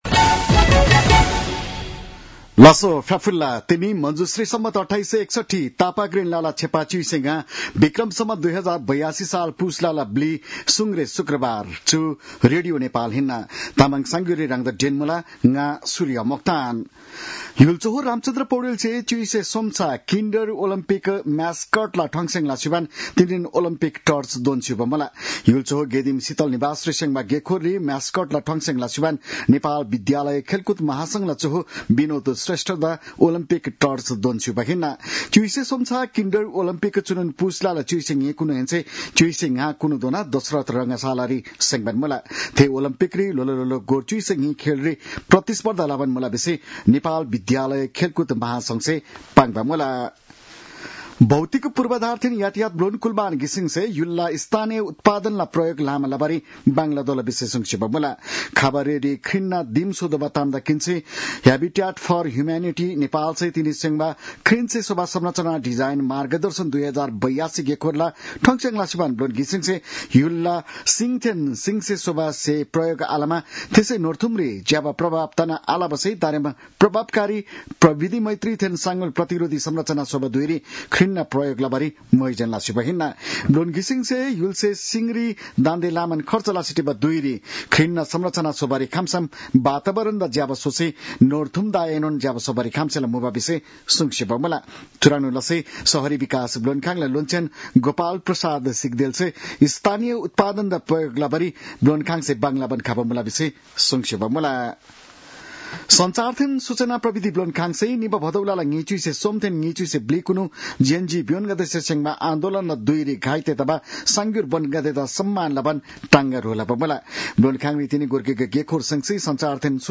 तामाङ भाषाको समाचार : ४ पुष , २०८२
Tamang-news-9-04.mp3